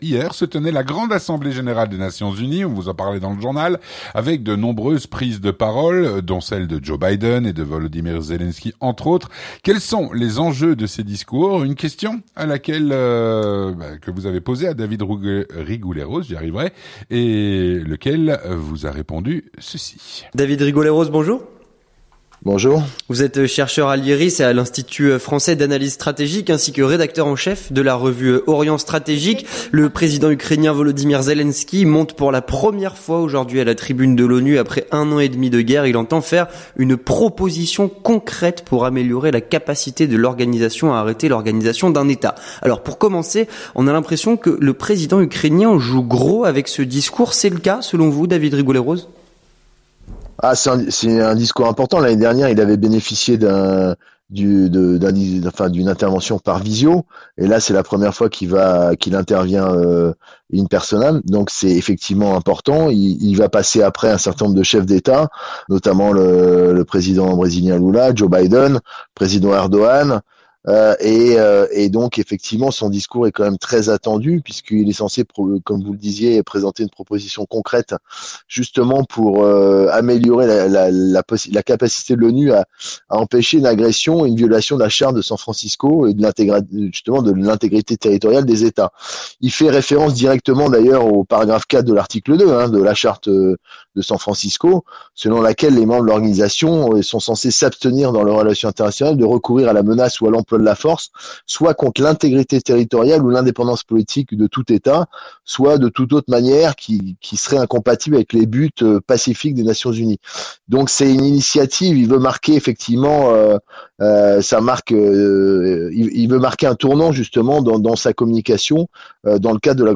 3. L'entretien du 18h